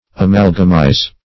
Amalgamize \A*mal"ga*mize\, v. t.